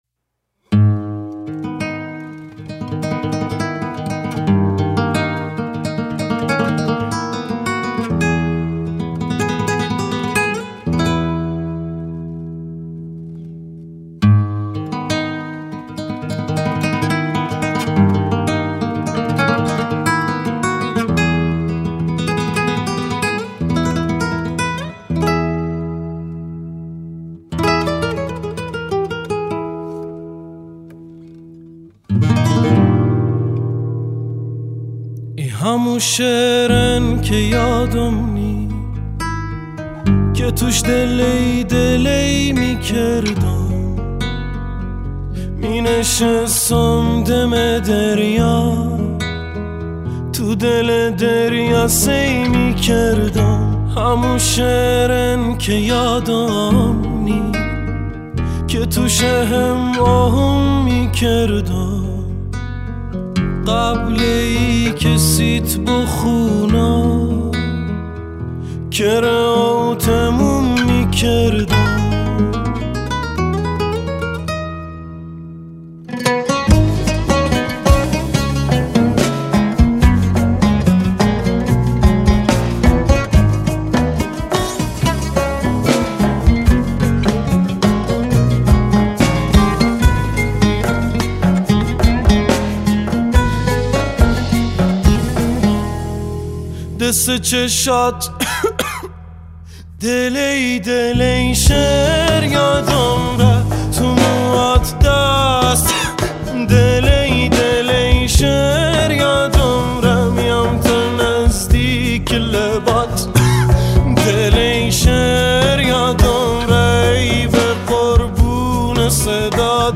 پرکاشن، همخوان
گیتار باس، عود
نی‌انبان، نی جفتی، عود